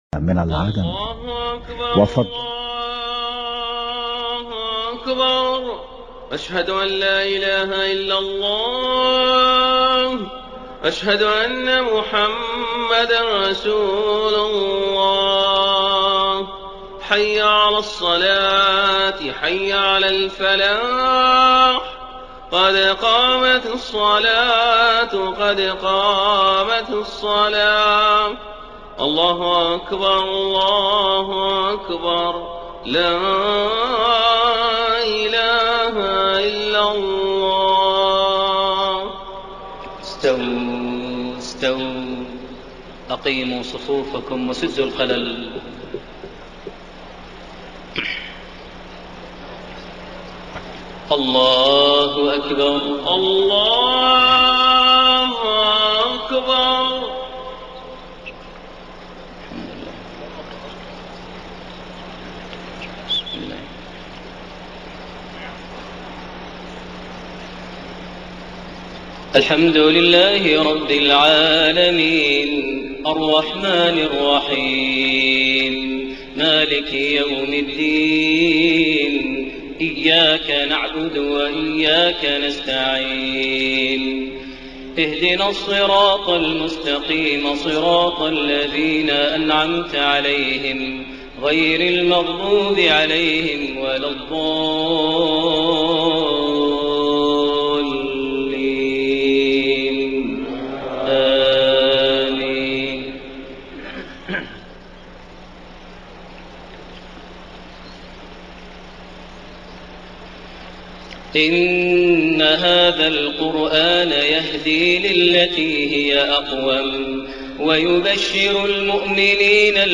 صلاة الفجر 9 شعبان 1433هـ من سورة الإسراء 9-30 > 1433 هـ > الفروض - تلاوات ماهر المعيقلي